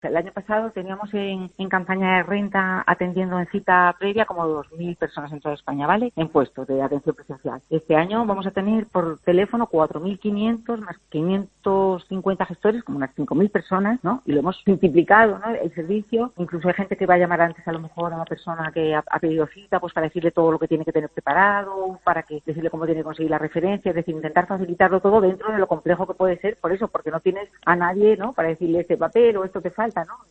Carmen Guillén, delegada especial de la Agencia Tributaria en Canarias